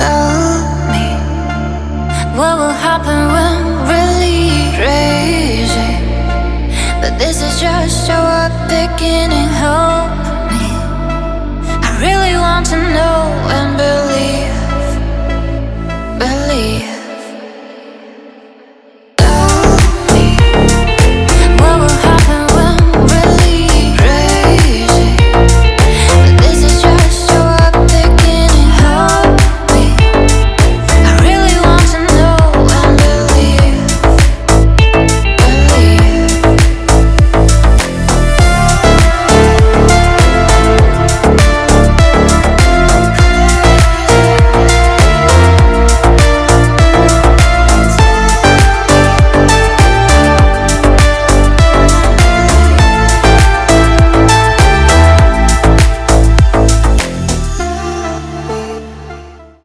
• Категория: Клубные рингтоны